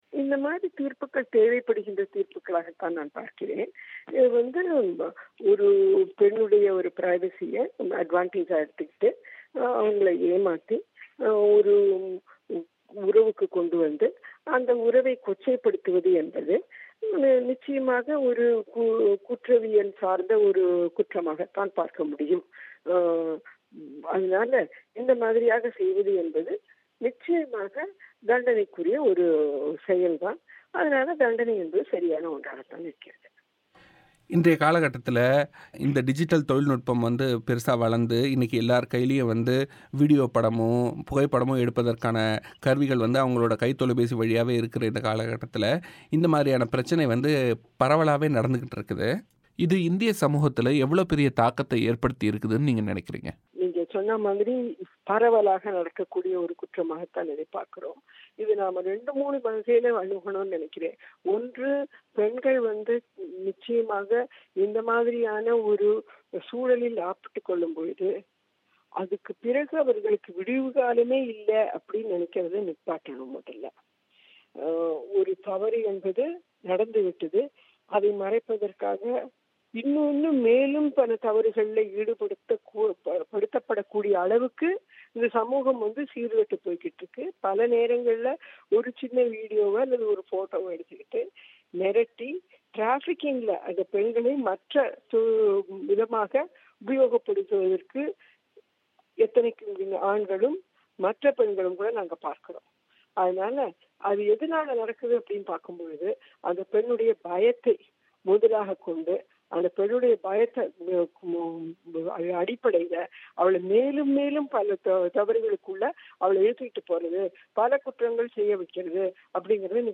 பேட்டி.